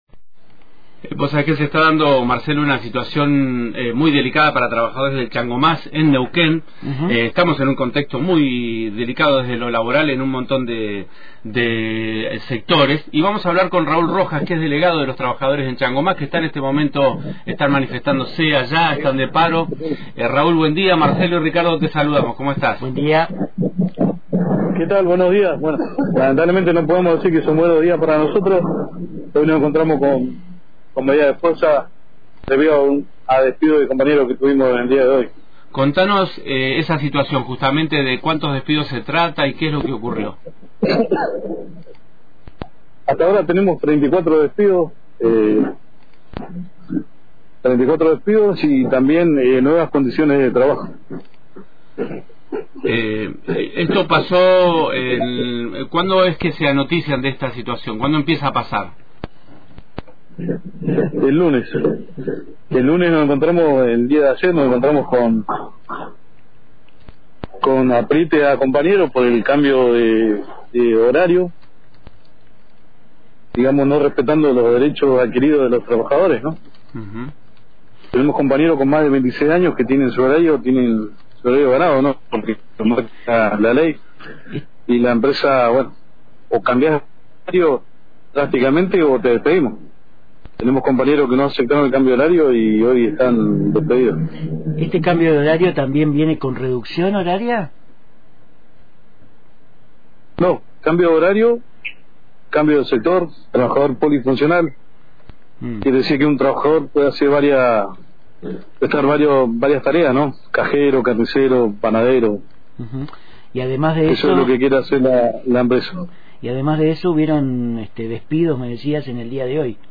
Continuarán las medidas de fuerza que bloquean la entrada al Hipermercado hasta que se de una respuesta favorable a los trabjadores. Escuchá la entrevista